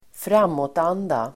Uttal: [²fr'am:åtan:da]